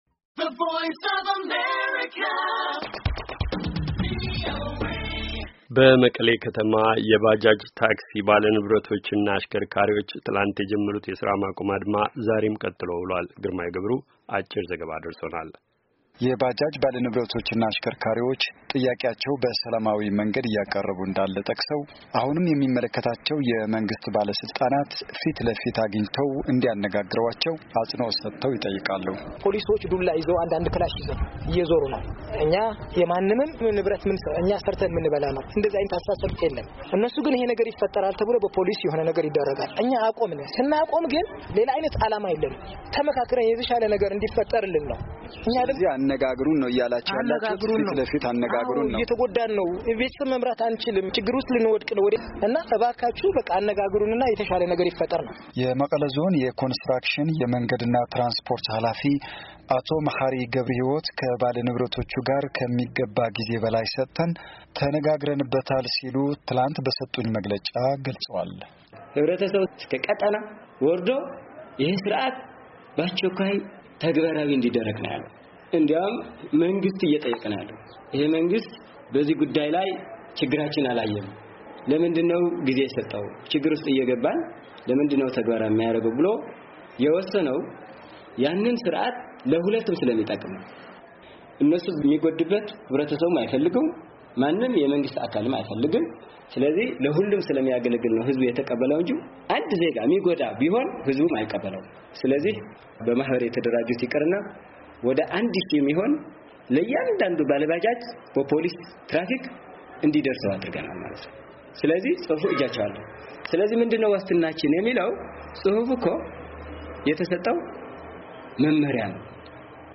አጭር ዘገባ